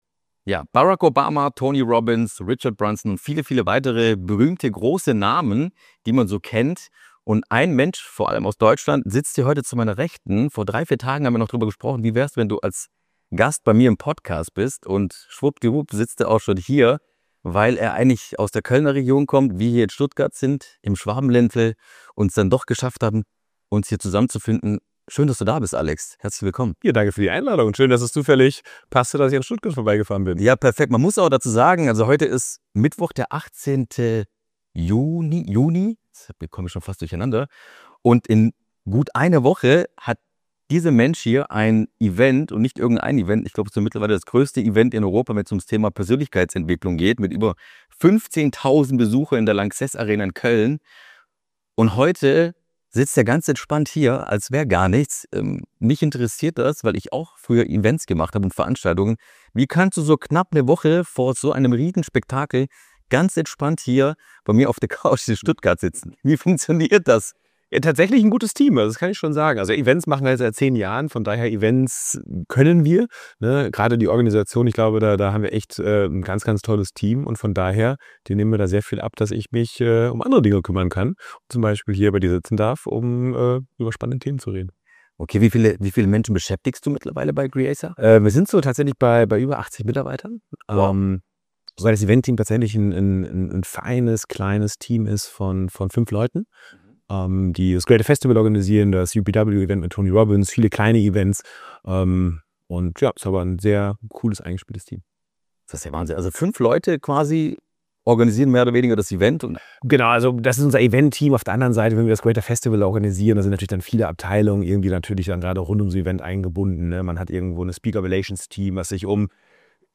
Ein Gespräch über Selbstführung, unternehmerische Visionen und den Mut, neu zu denken. Offen, inspirierend und direkt.